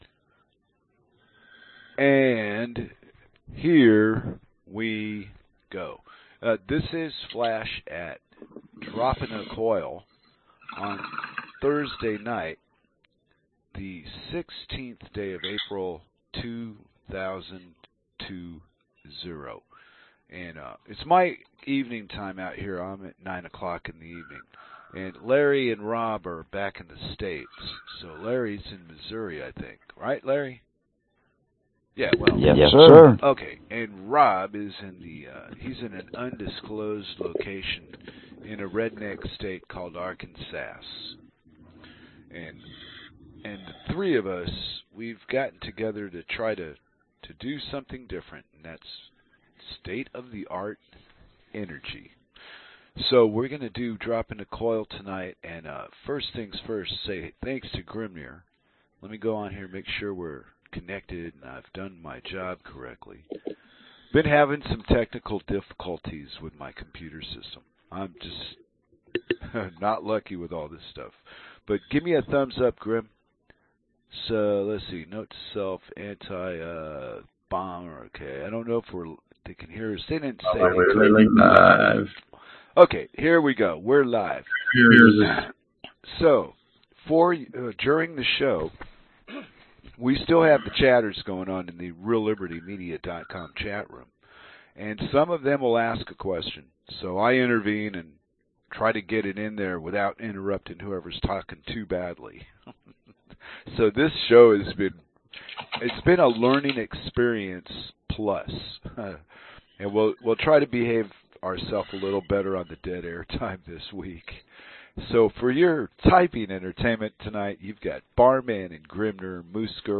Album Dropping a Coil Genre Talk